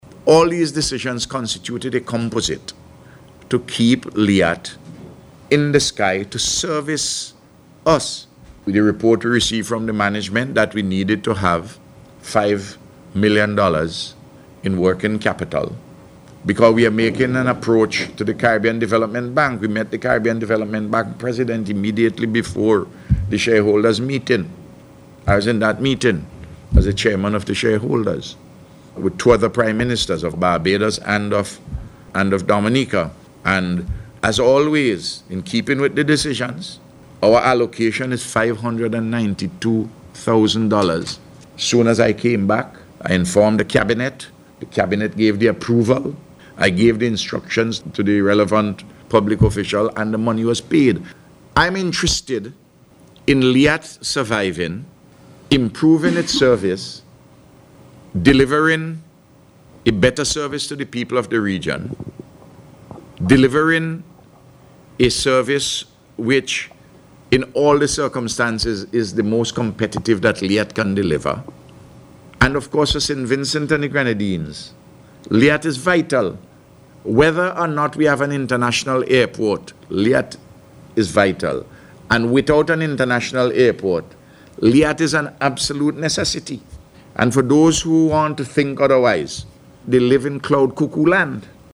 Prime Minister Gonsalves told Reporters on Tuesday that the shareholder Governments are also seeking additional resources from the Caribbean Development Bank. The Prime Minister said LIAT has to be restructured in a comprehensive manner and the restructuring has been taking place.